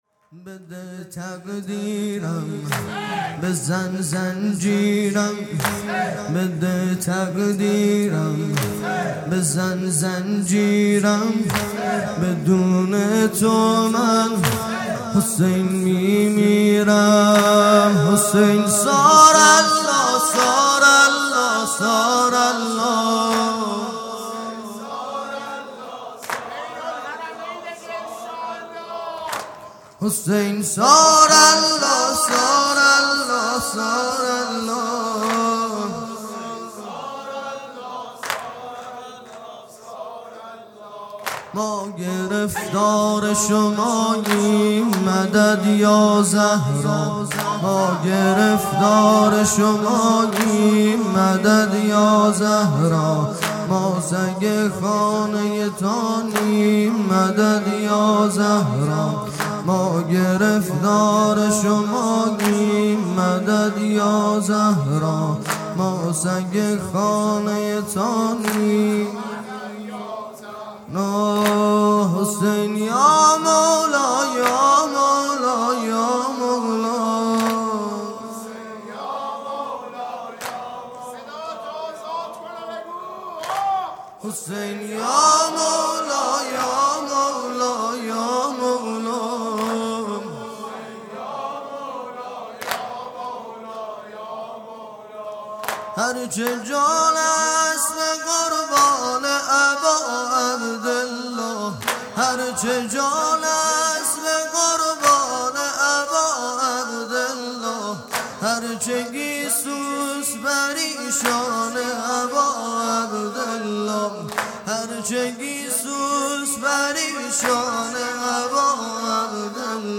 جلسه هفتگی